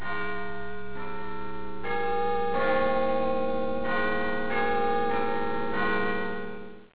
chimes on the hour and the quarter-hour.
BigBen.wav